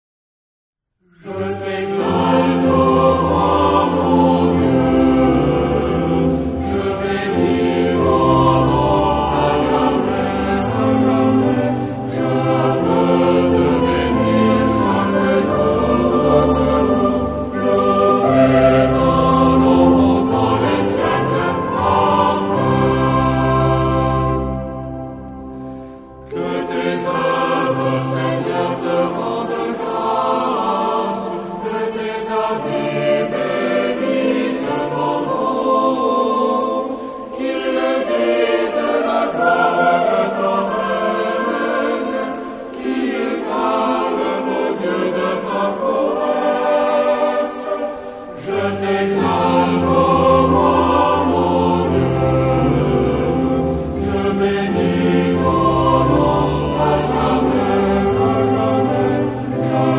Genre-Style-Form: Sacred ; Canticle
Type of Choir: SATB  (4 mixed voices )
Tonality: C major ; F major